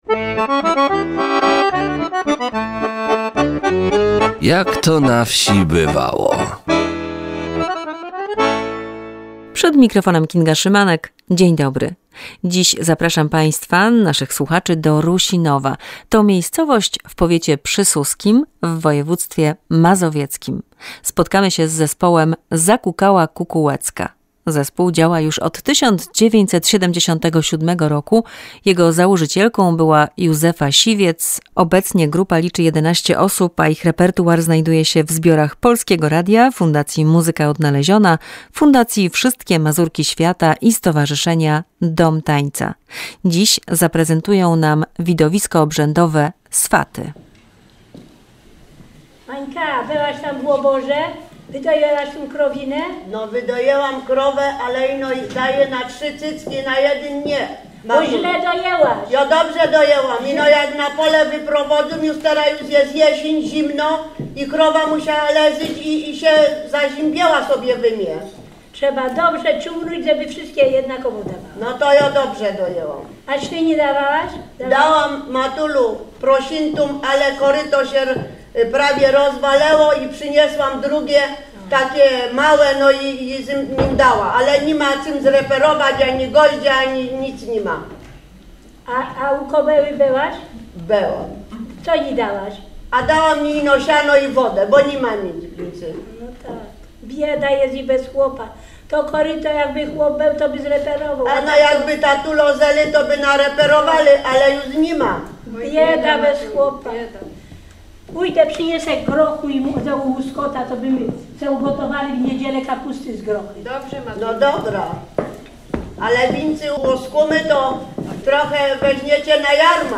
Spektakl „Swaty” w wykonaniu zespołu Zakukała Kukułecka z Rusinowa.